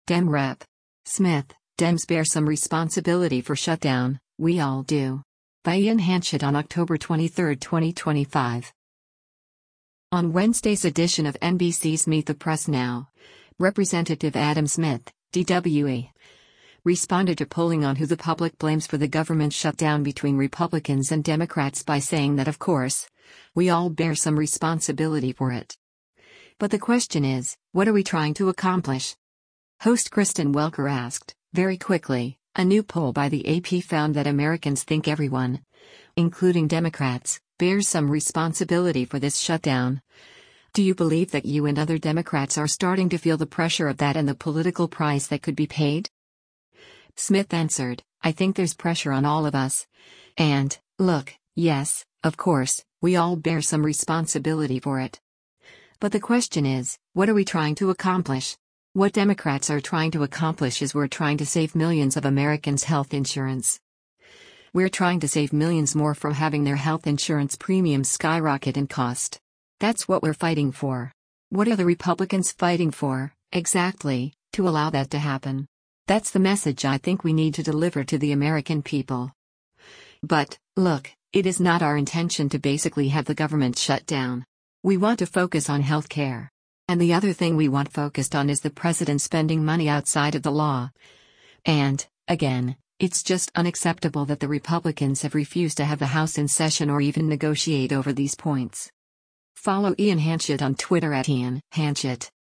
Host Kristen Welker asked, “Very quickly, a new poll by the AP found that Americans think everyone, including Democrats, bears some responsibility for this shutdown. … Do you believe that you and other Democrats are starting to feel the pressure of that and the political price that could be paid?”